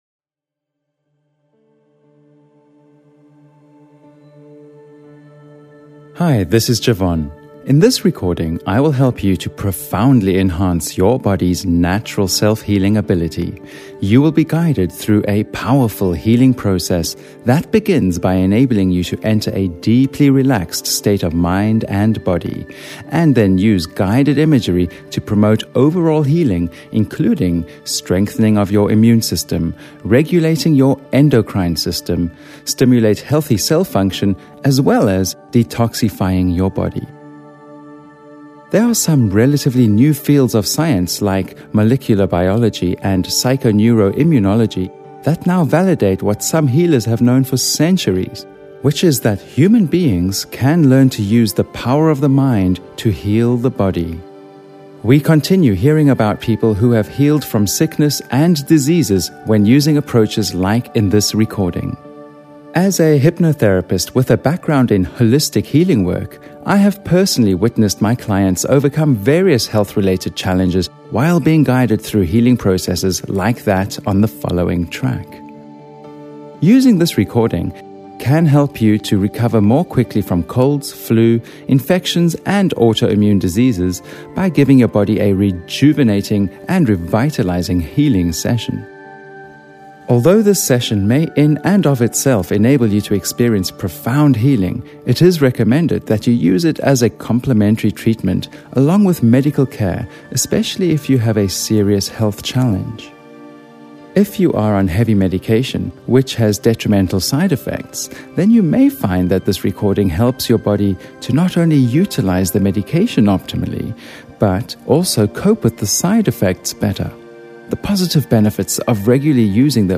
Involves hypnotherapy (guided relaxation, healing visualisations, and therapeutic suggestion techniques) to help strengthen your immune system, regulate your endocrine system, promote healthy cell function, and detoxify your body. Includes a complete Chakra balancing session.